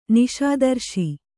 ♪ niśadarśi